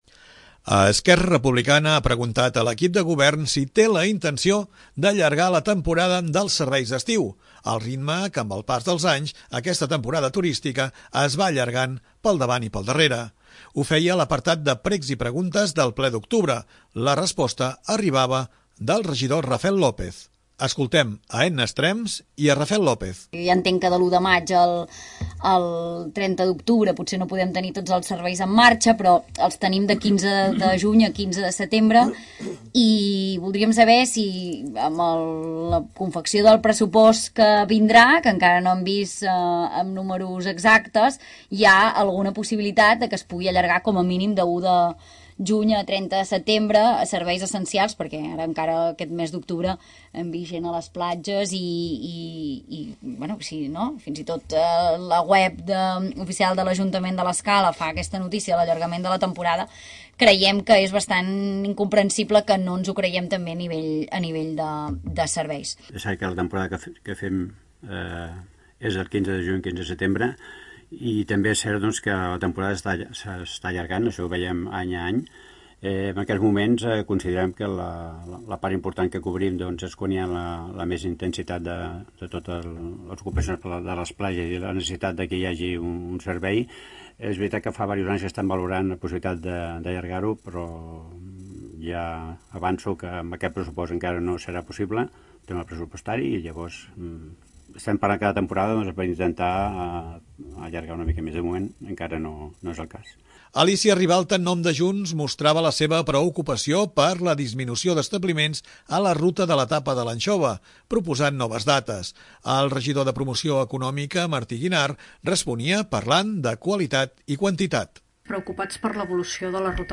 L'allargament dels serveis de temporada coincidint amb l'època que l'Escala rep més visitants, va ser un dels temes tractats  a l'apartat de Precs i Preguntes del ple municipal del mes d'octubre.
Ho feia a l'apartat de Precs i Preguntes del ple d'octubre.